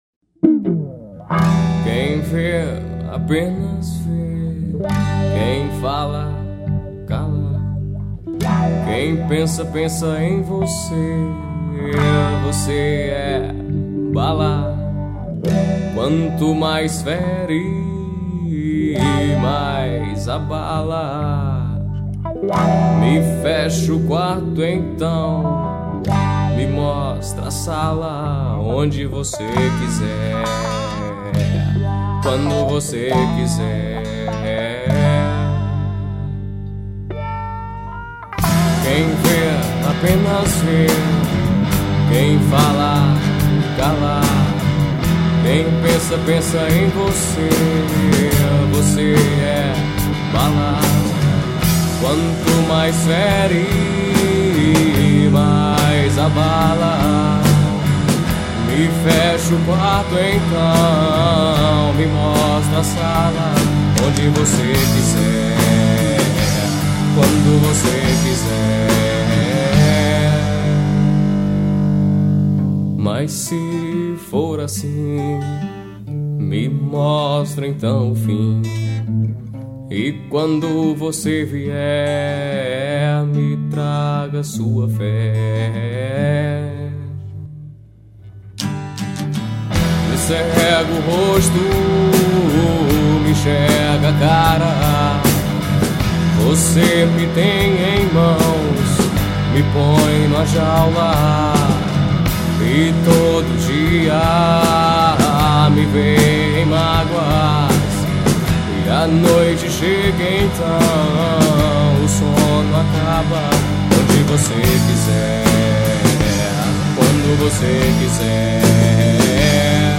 2506   04:06:00   Faixa: 4    Rock Nacional